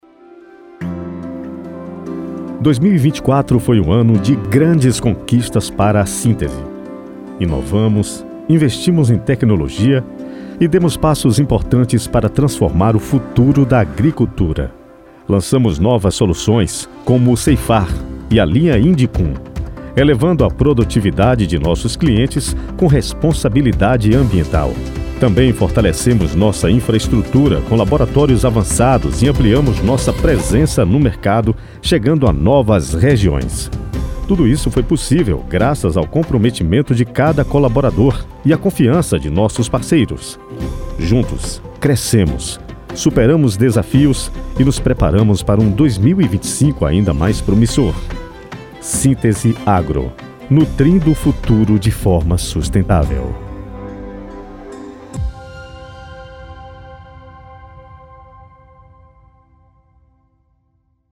SPOT_SINTESE.:
Spot Comercial